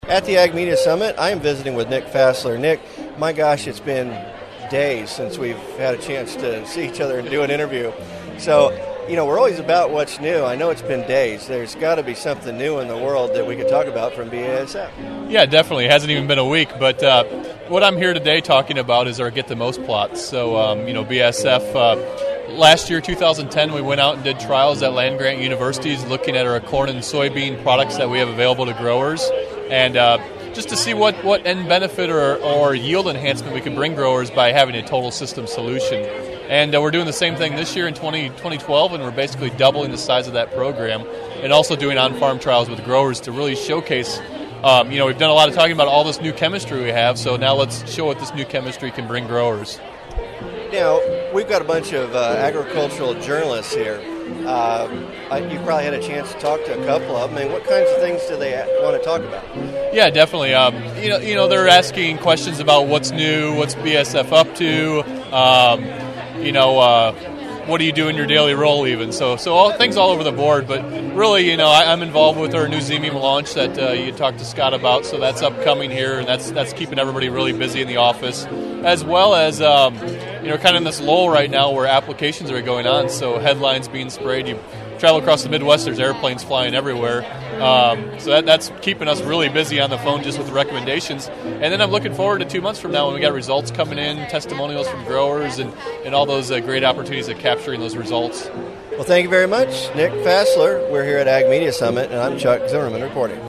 Meet three of my amigos from the Ag Media Summit.